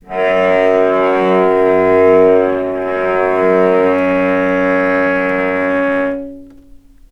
healing-soundscapes/Sound Banks/HSS_OP_Pack/Strings/cello/sul-ponticello/vc_sp-F#2-mf.AIF at a9e67f78423e021ad120367b292ef116f2e4de49
vc_sp-F#2-mf.AIF